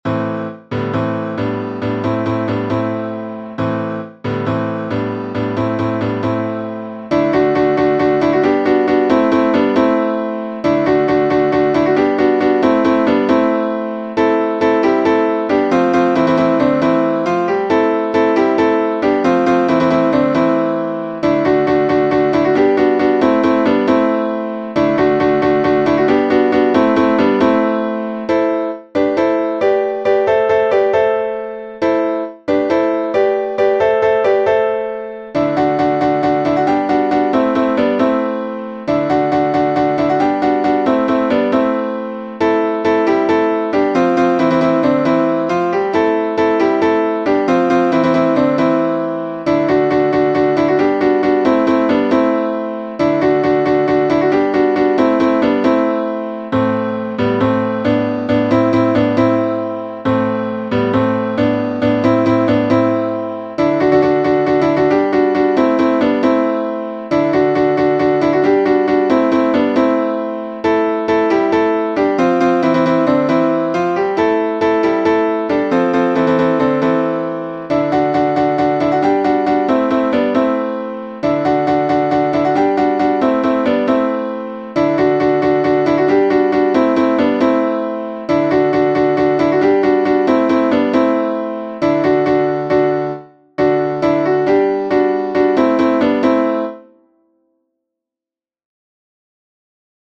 version piano